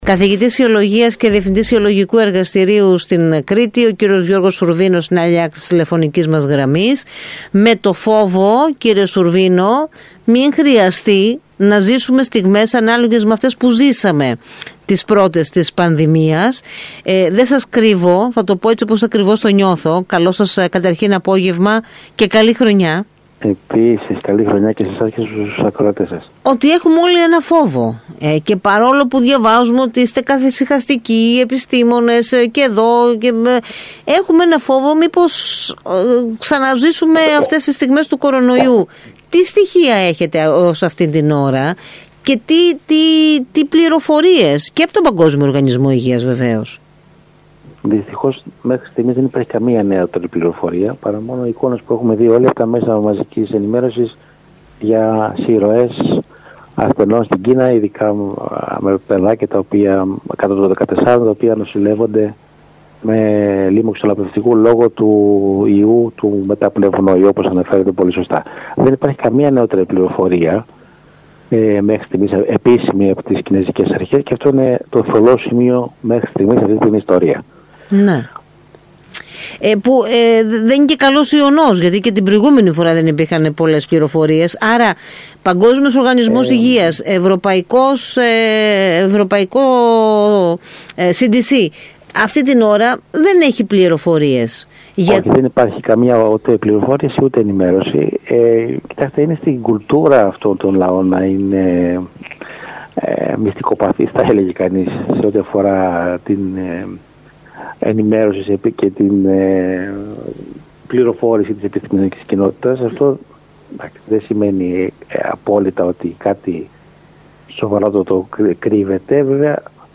μιλώντας στον ΣΚΑΪ Κρήτης 92,1